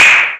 Clap 02.wav